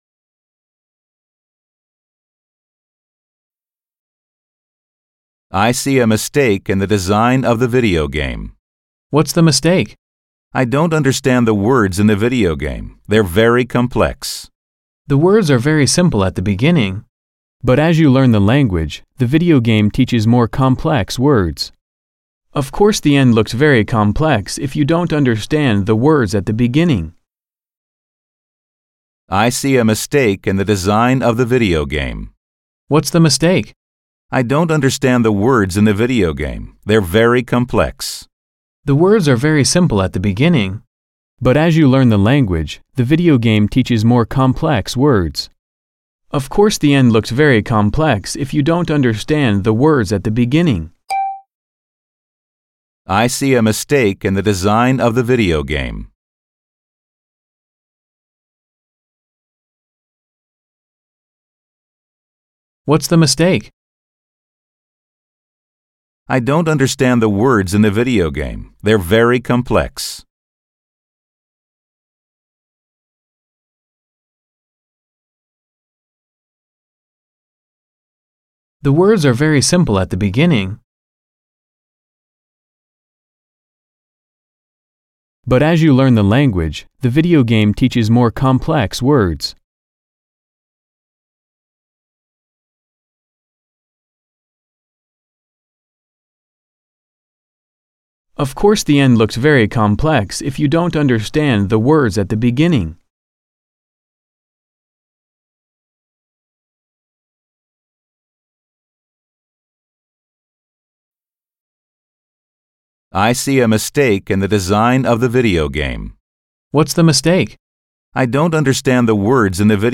11_-_Lesson_2_Conversation.mp3